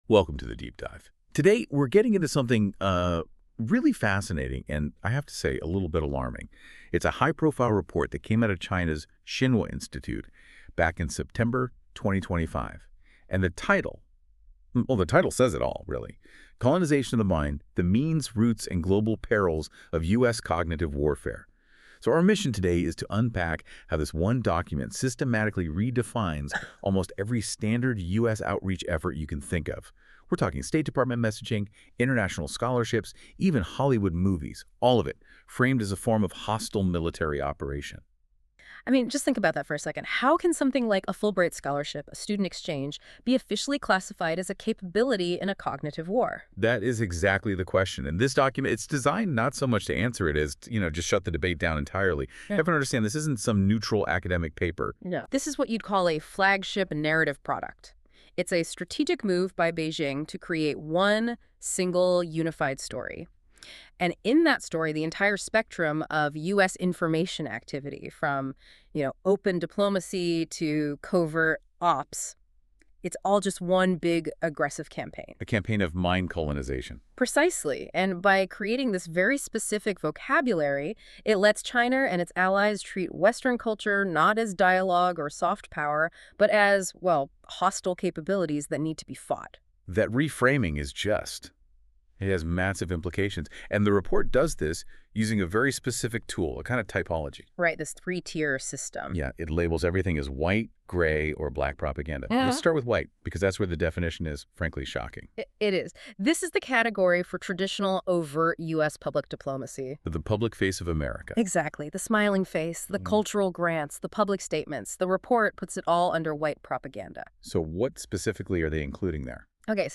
Related Podcasts These podcast episodes are produced with AI voice technology. While we strive for accuracy, please be aware that the voices and dialogue you hear are computer-generated.